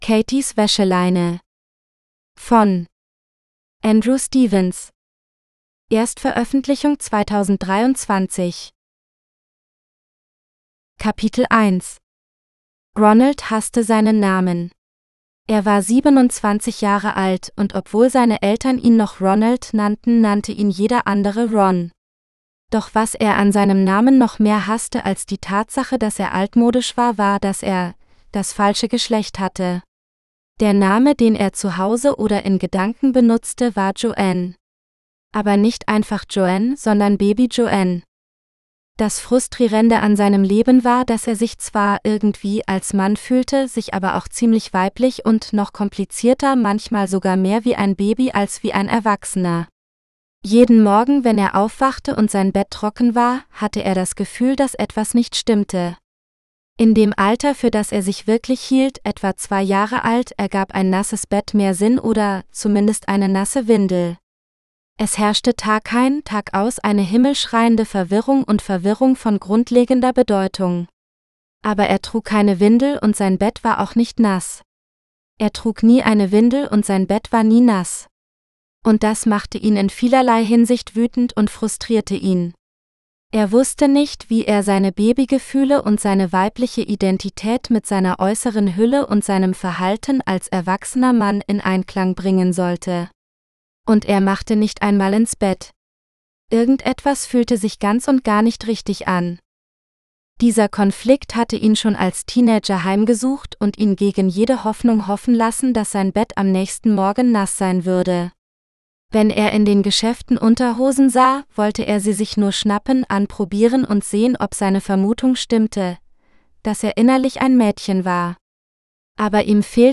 Katies Wäscheleine (AUDIOBOOK – female): $US5.75